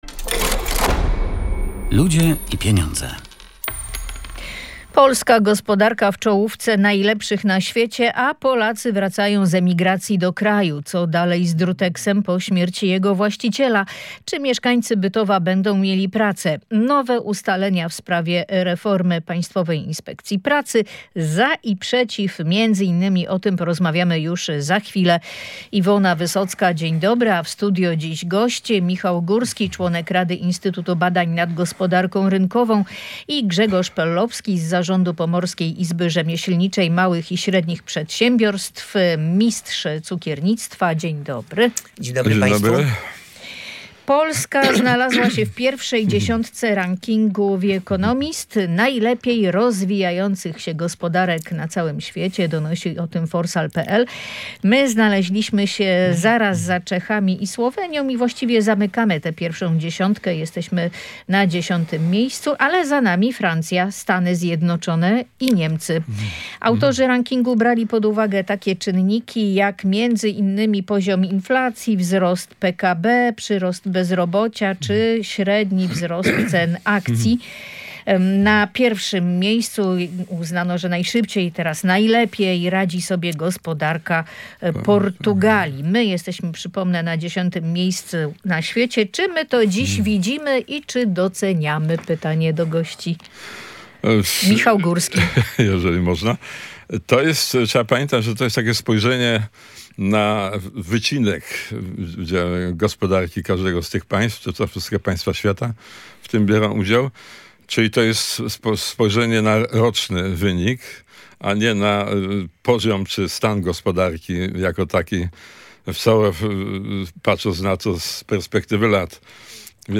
Na powyższy temat rozmawiali goście audycji „Ludzie i Pieniądze”: